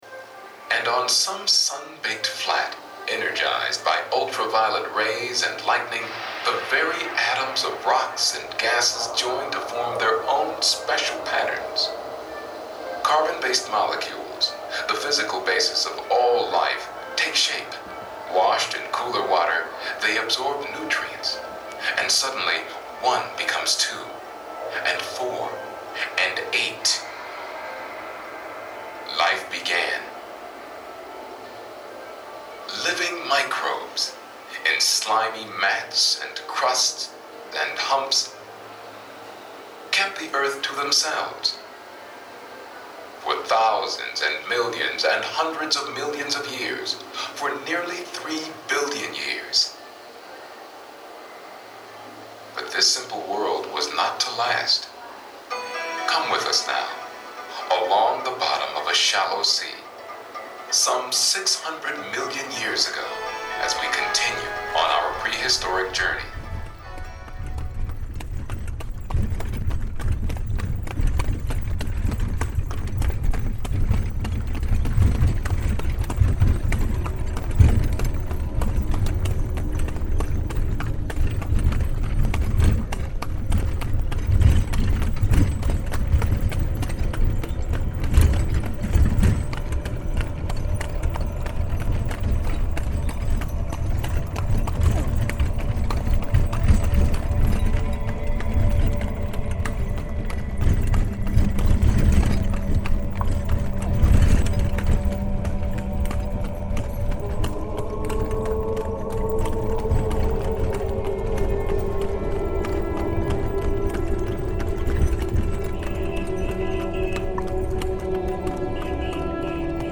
asks to use some of my field recordings from the SoundTransit project in a remix program for The Night Air experimental radio program at the ABC.